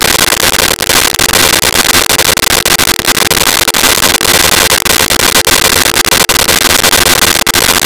Phone Dial Tone
Phone Dial Tone.wav